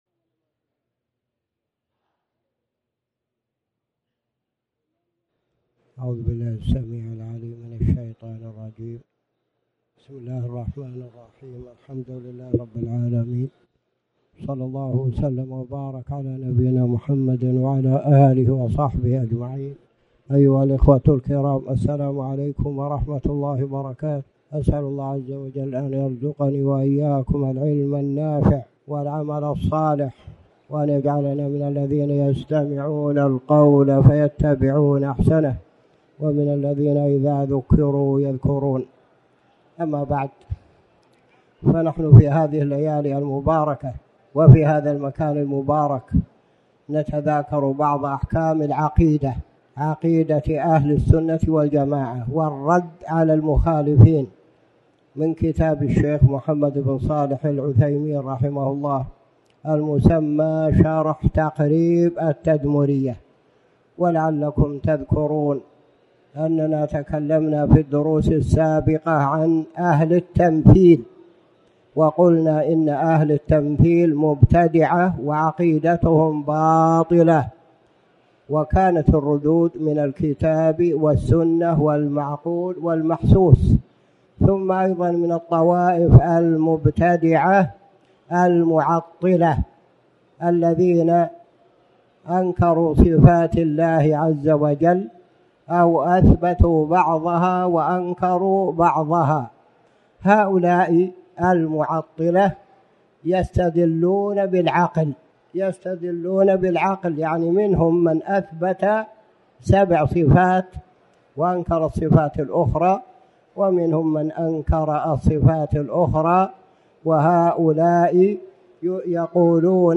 تاريخ النشر ٩ ذو القعدة ١٤٣٩ هـ المكان: المسجد الحرام الشيخ